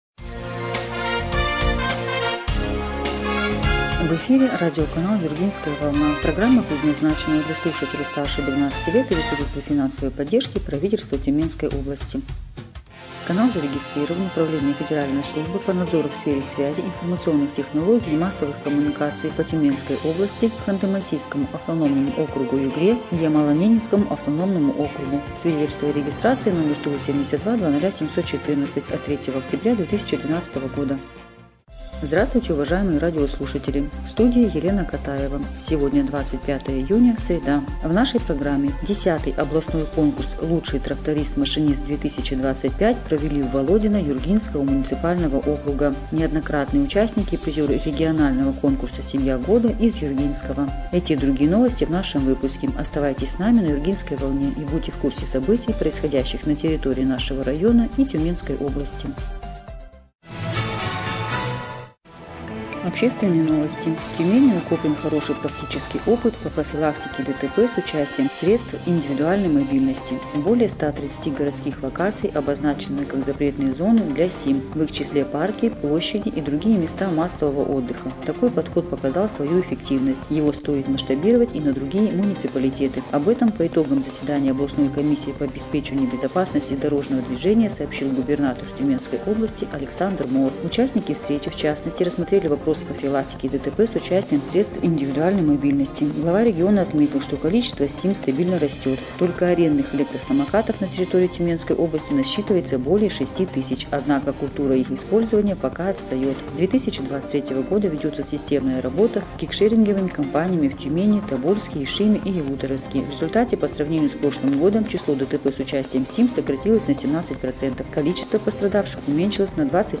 Эфир радиопрограммы "Юргинская волна" от 25 июня 2025 года